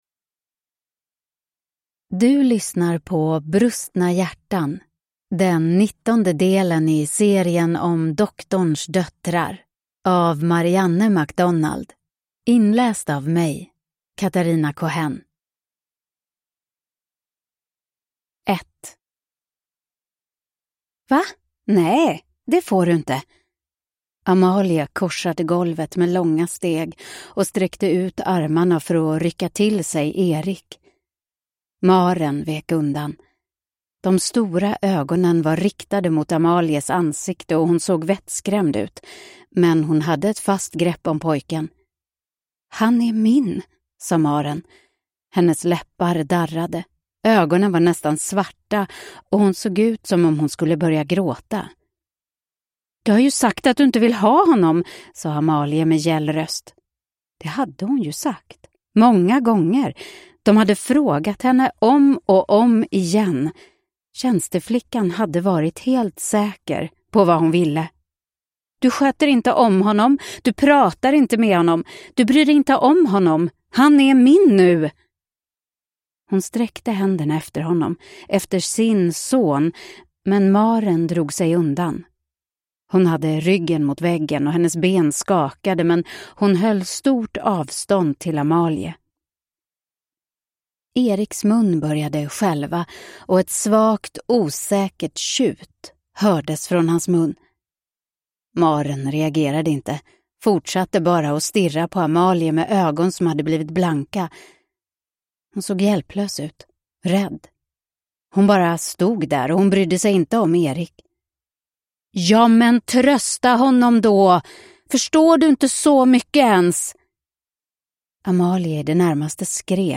Brustna hjärtan (ljudbok) av Marianne MacDonald